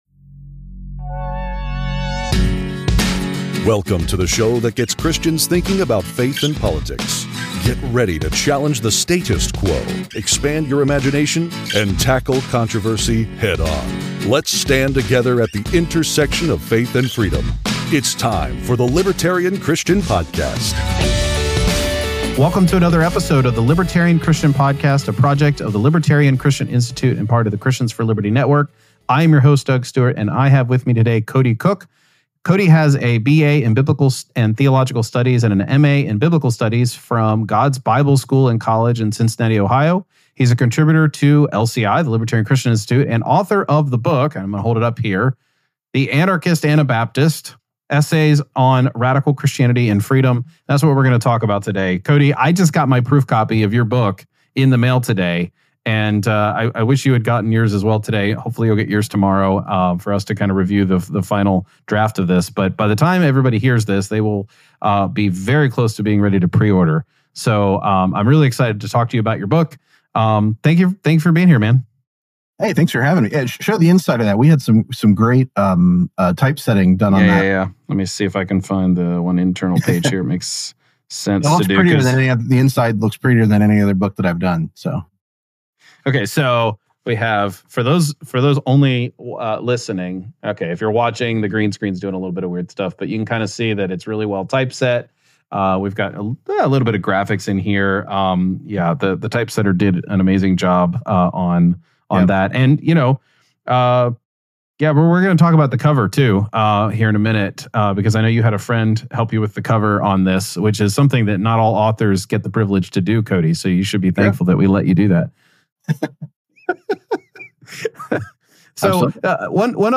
A conversation about my upcoming book which is being published by the Libertarian Christian Institute.